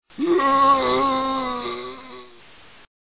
mgroan6.mp3